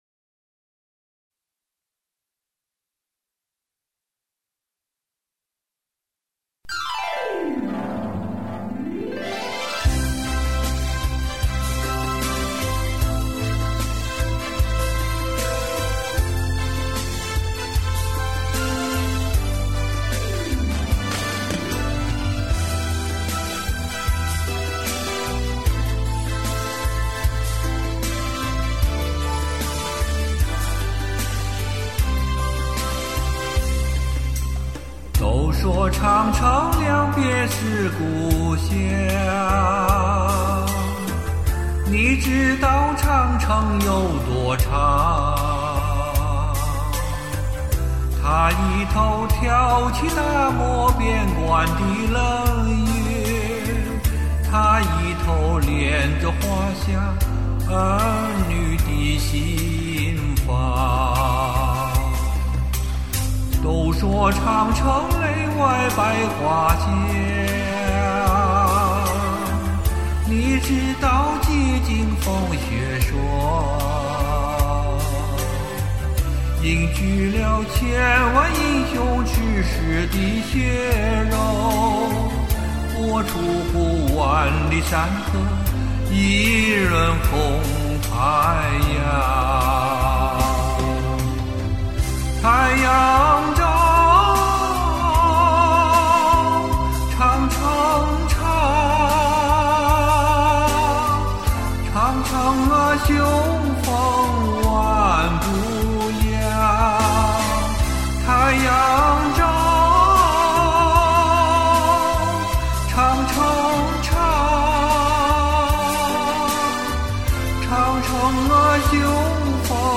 宽广辽阔，像是在群山环抱中放声高歌，好听。
第一次听男声唱这歌,侠骨柔情,棒极了!
很抒情悠扬的演唱。